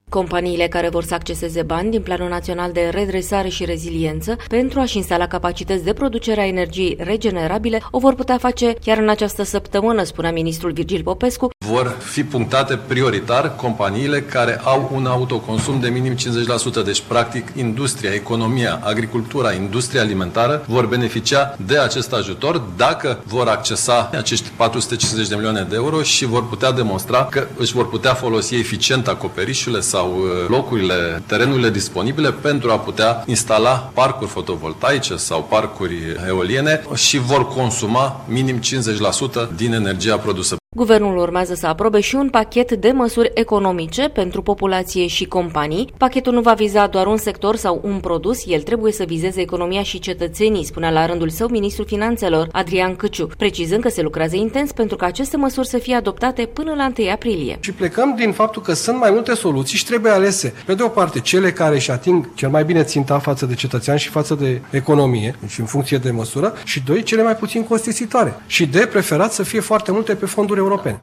Ministrul finanțelor, Adrian Cîciu.